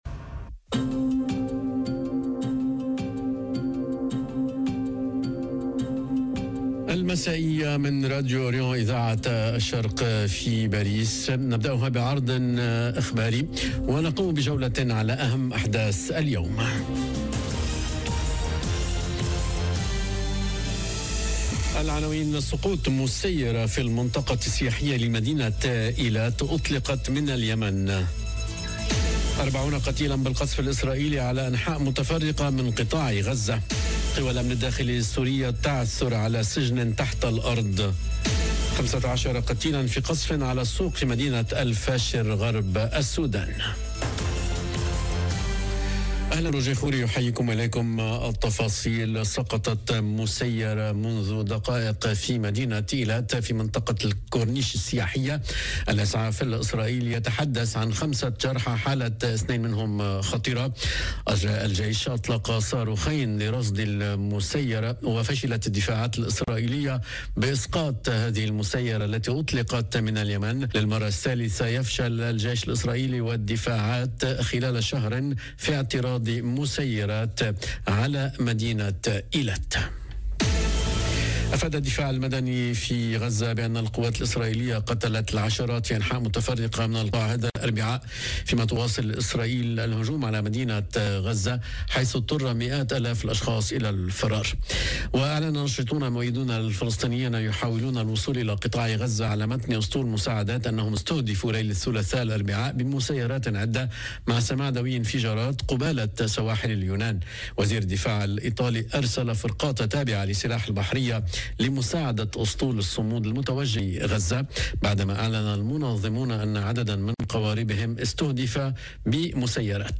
نشرة أخبار المساء: مسيرة تسقط في إيلات، وسجن سوري تحت الأرض، و40 قتيلا بالقصف الإسرائيلي على أنحاء متفرقة من قطاع غزة - Radio ORIENT، إذاعة الشرق من باريس